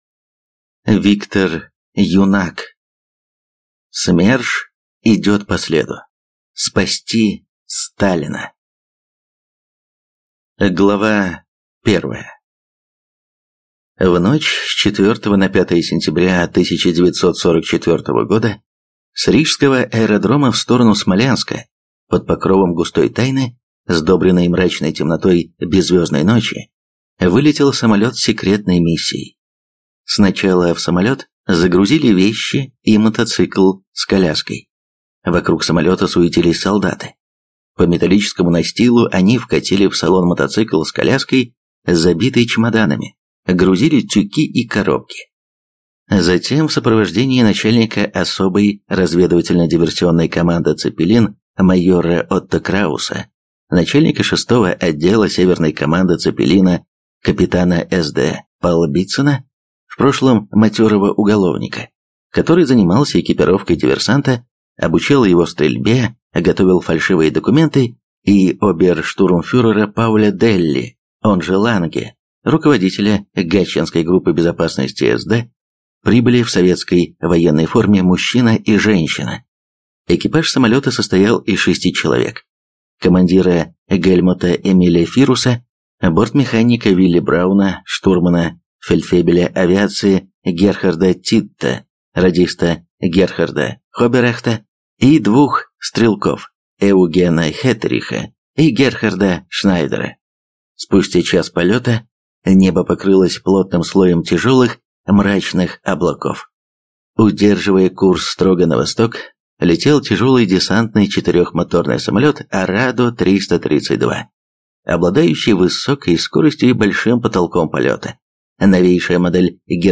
Аудиокнига СМЕРШ идет по следу. Спасти Сталина!